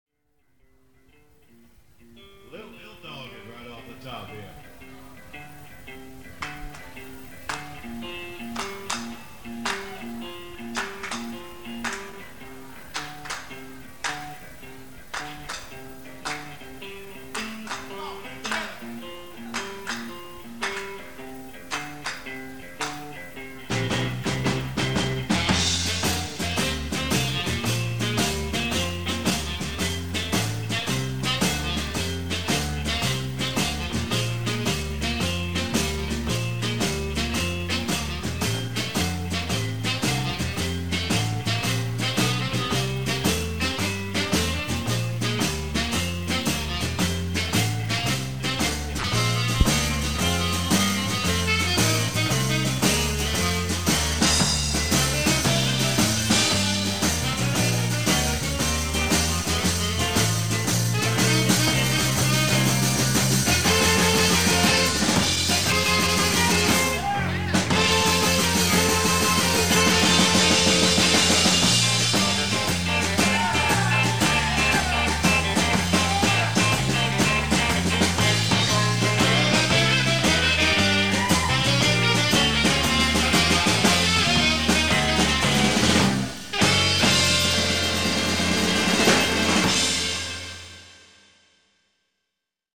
instrumental classic